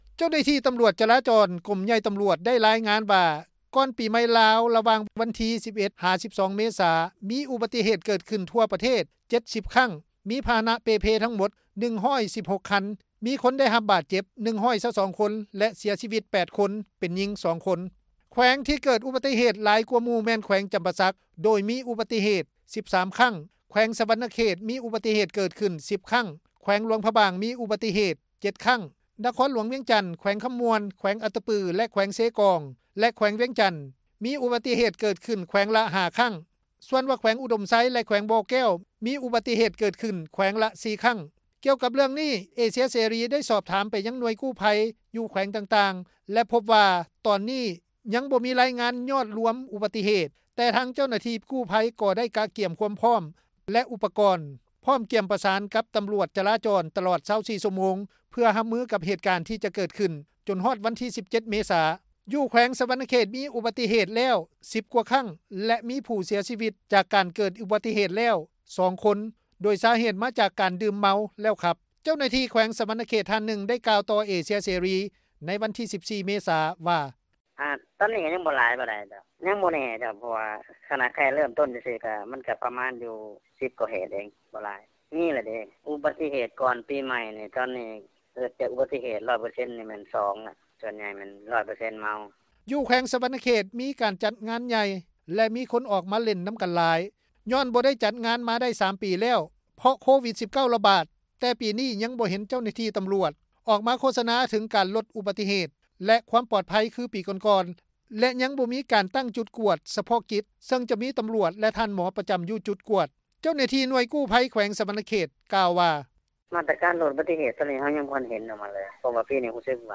ເຈົ້າໜ້າທີ່ ແຂວງສວັນນະເຂດ ທ່ານນຶ່ງ ໄດ້ກ່າວຕໍ່ວິທຍຸ ເອເຊັຽເສຣີ ໃນວັນທີ 14 ເມສາ ວ່າ: